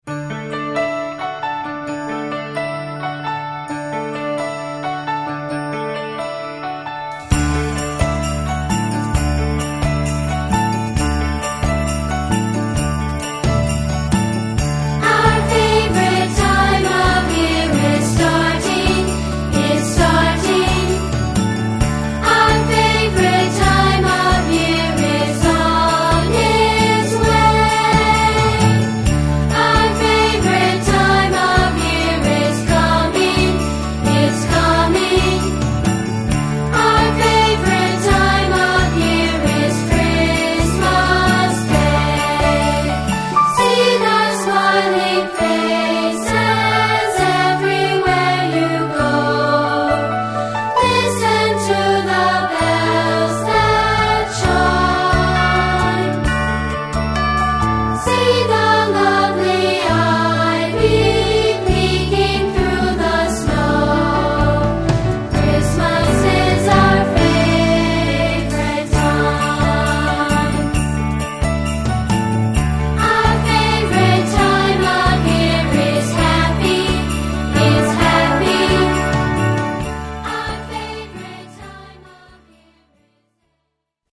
A Rockin’ Holiday Fantasy For Young Voices
(the festive opener)